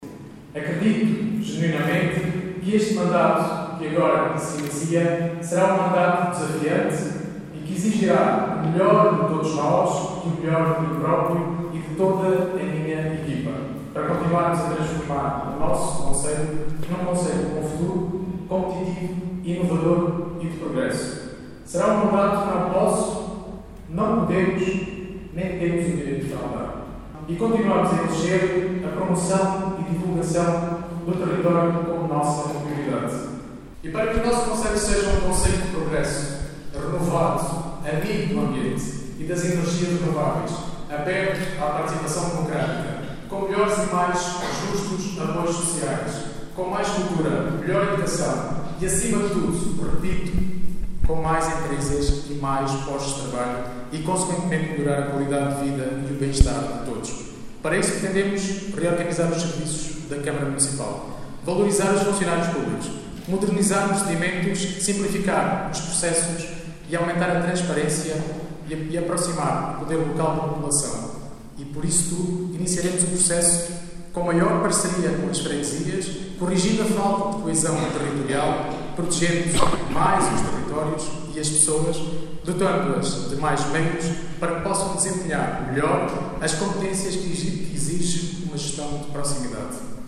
Teve lugar ontem, o Ato de Instalação dos Órgãos do Município de Vidigueira, Assembleia e Câmara Municipal, com a tomada de posse dos novos eleitos para o quadriénio 2021/2025.